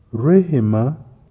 Naši kolegové z Ugandy nám nahráli správnou výslovnost vybraných jmen.